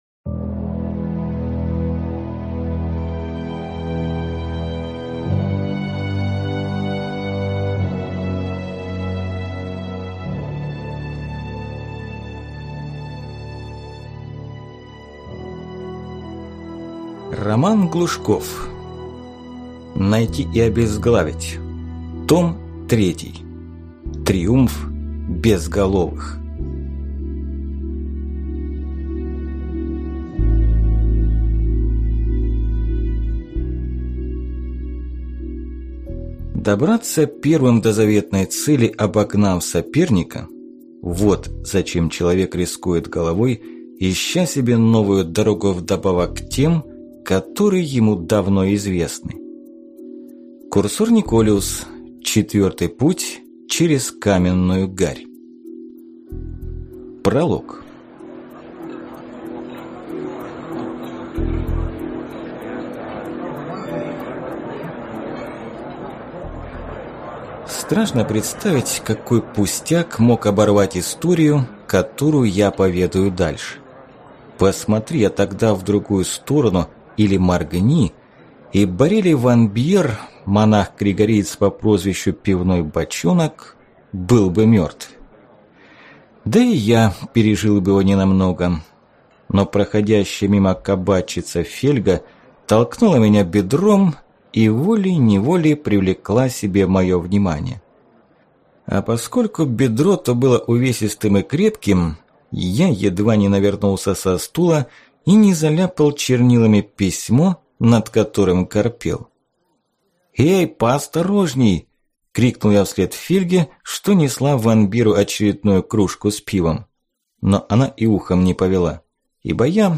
Аудиокнига Найти и обезглавить! Том 3. Триумф безголовых | Библиотека аудиокниг